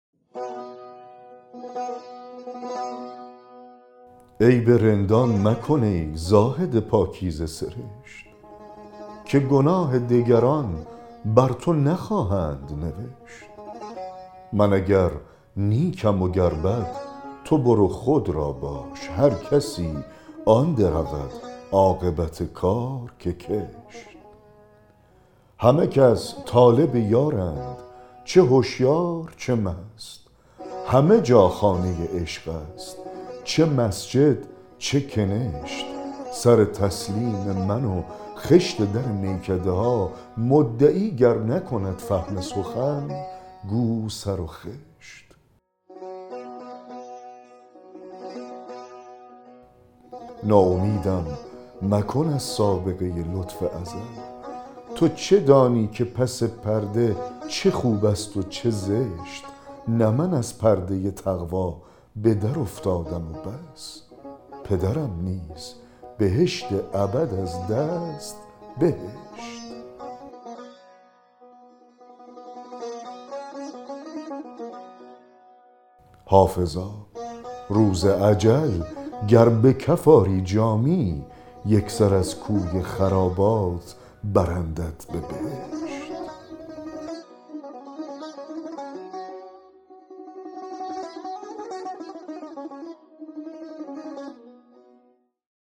دکلمه غزل 80 حافظ
دکلمه غزل عیب رندان مکن ای زاهد پاکیزه سرشت
برای دکلمه غزل در تلفظ بهشت اول آکسان یا شدت تلفظ روی هشت است و در بهشت دوم آکسان روی ب است